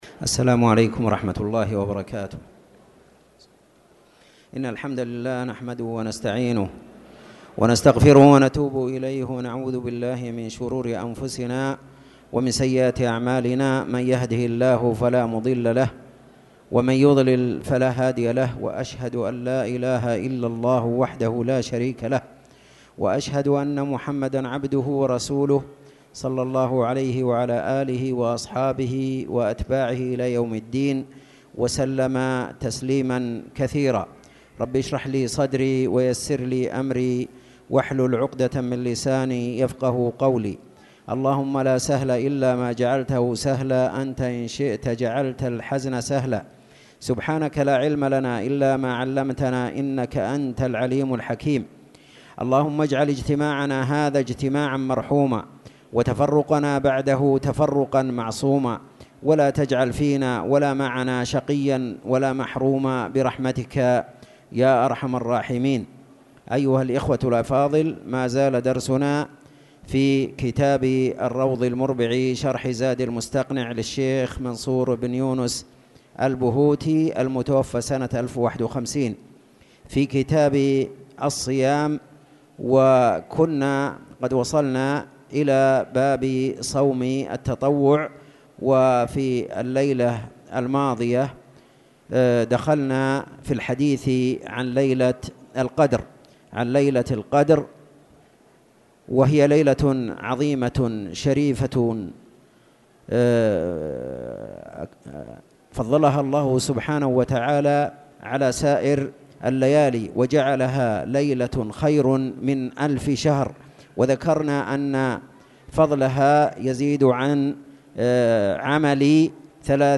تاريخ النشر ١٠ جمادى الأولى ١٤٣٨ هـ المكان: المسجد الحرام الشيخ